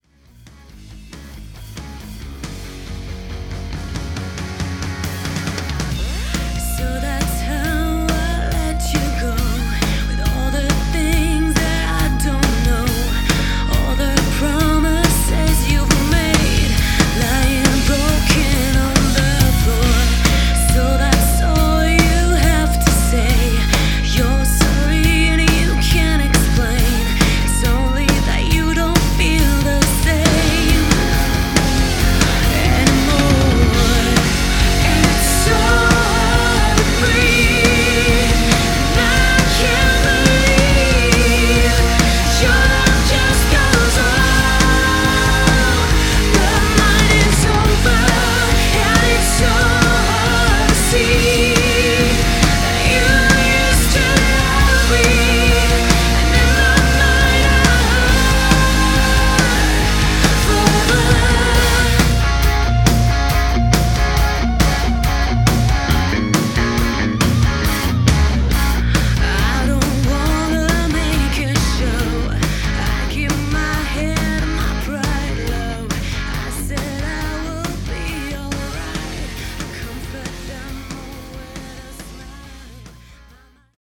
crystalline and captivating voice
Guitar
Bass
Drums